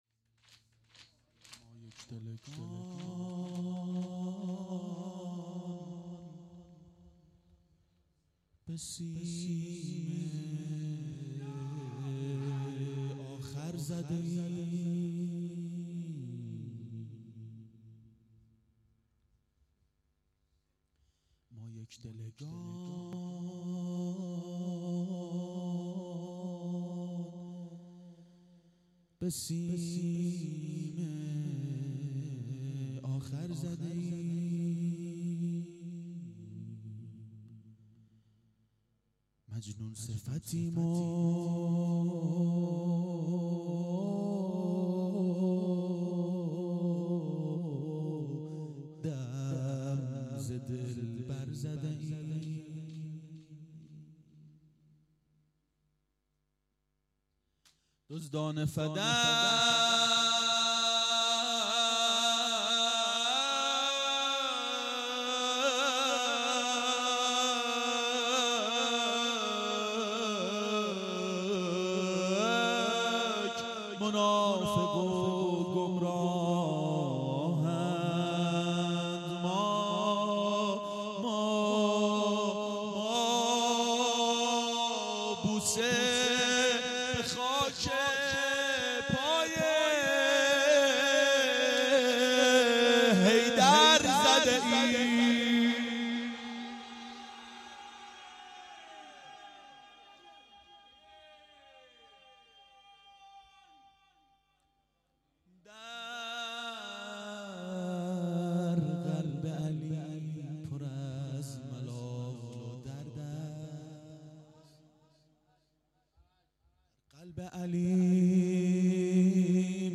• دهه اول صفر سال 1391 هیئت شیفتگان حضرت رقیه سلام الله علیها (شب اول)